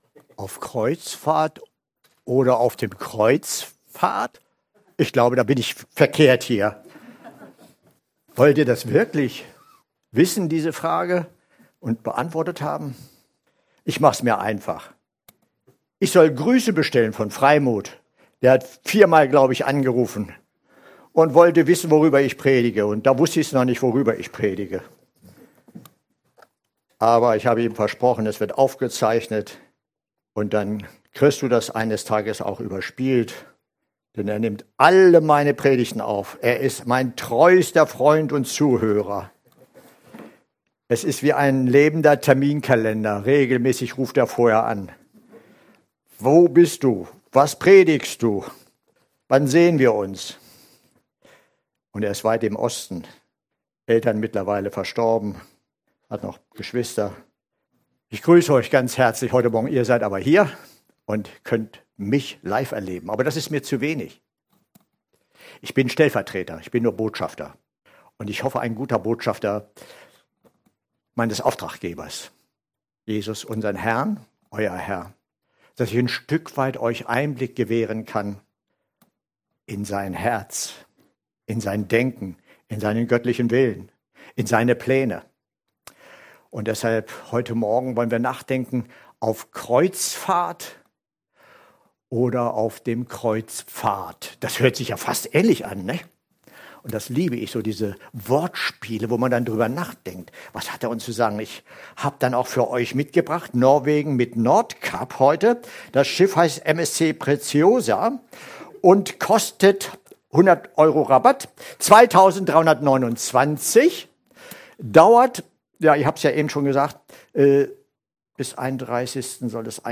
PREDIGTEN zum Nachhören als Downloadliste – FEZ